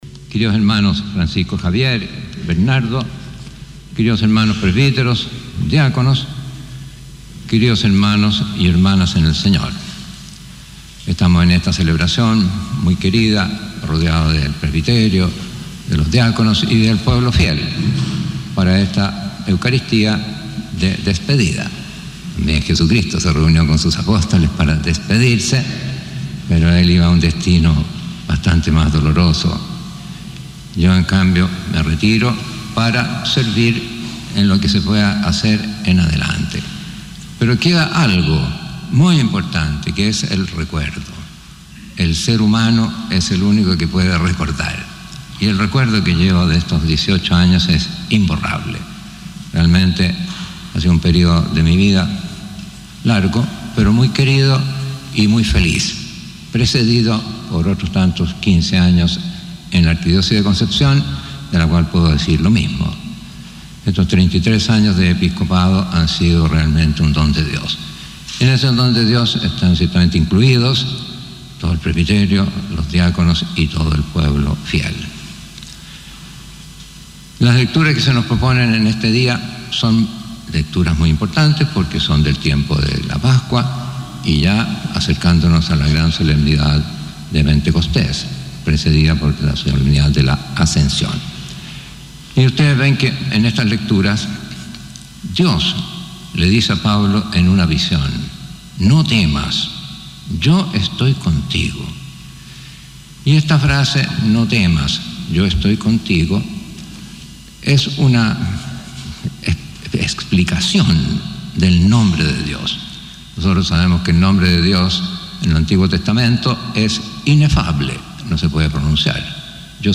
Durante la ceremonia, Monseñor Bacarreza, quien presidió la misa, compartió en su homilía reflexiones sobre su trayectoria en Los Ángeles, comparando su partida con la despedida de Jesús a sus discípulos, destacando su deseo de seguir sirviendo en nuevos caminos.
Homilía de despedida de monseñor Felipe Bacarreza